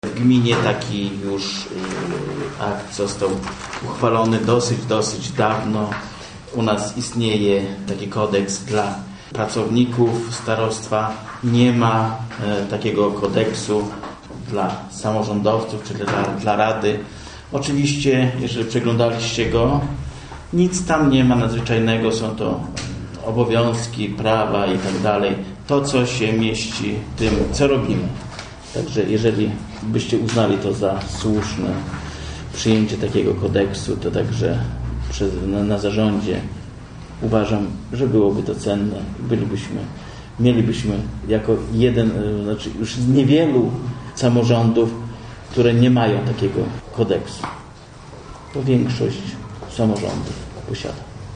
mówi Leszek Retel, przewodniczący RP